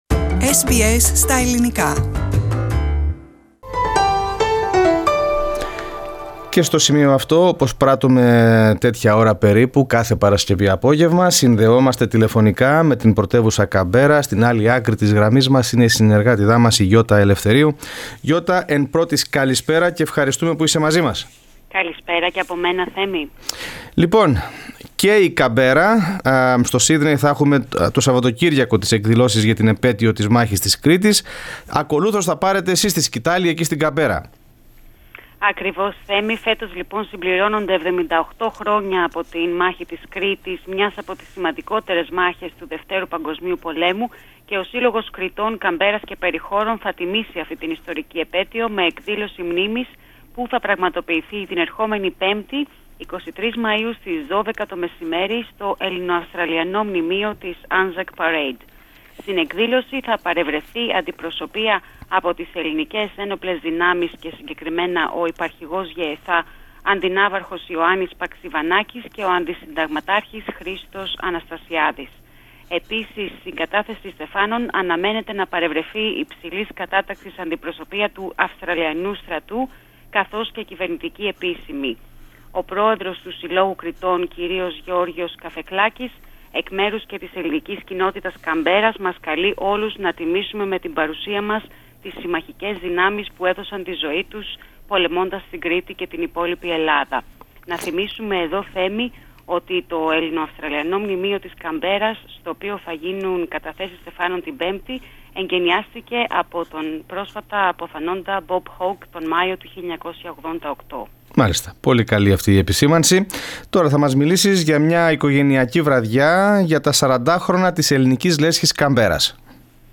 Ο Σύλλογος Κρητών Καμπέρας και Περιχώρων διοργανώνει εκδήλωση μνήμης για να τιμήσει τη συμπλήρωση 78 χρόνων από τη Μάχη της Κρήτης τον Β’ Παγκόσμιο Πόλεμο. Περισσότερα στην εβδομαδιαία μας ανταπόκριση απο την Καμπέρα.